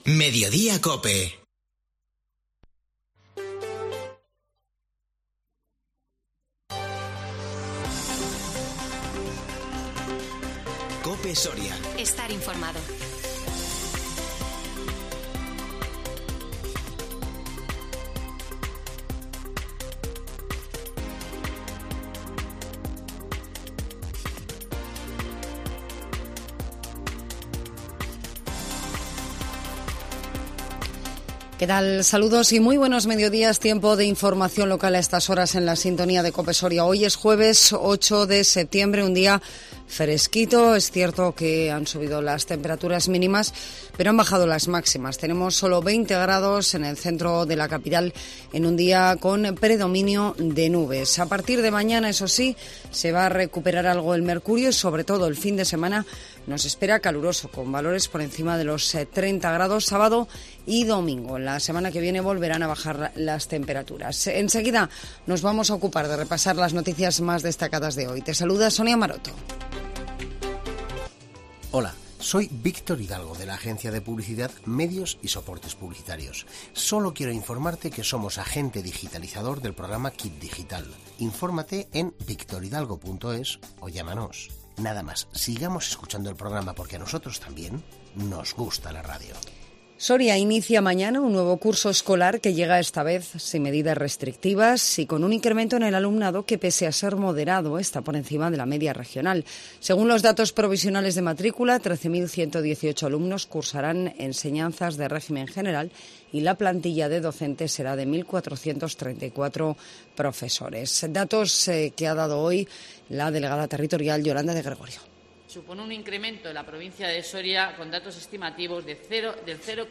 INFORMATIVO MEDIODÍA COPE SORIA 8 SEPTIEMBRE 2022